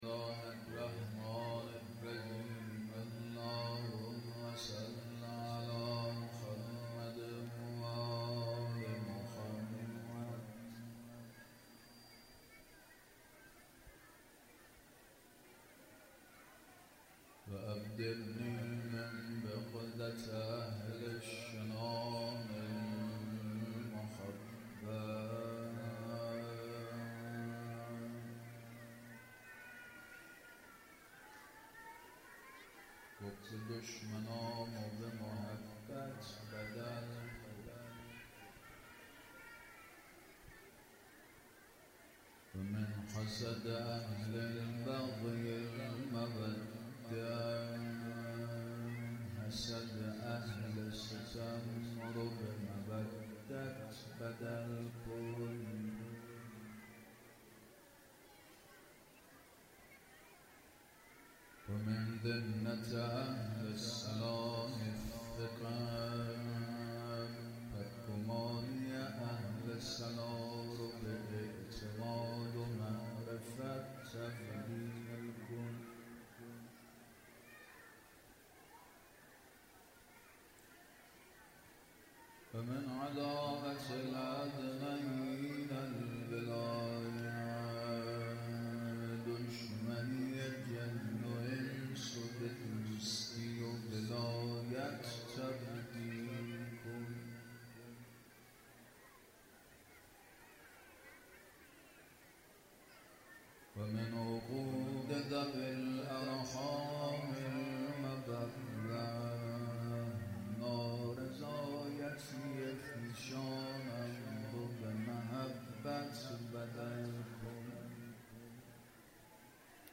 خیمه گاه - هیئت یا فاطمه الزهرا (س) - گزارش صوتی شب سوم محرم 1402
روضه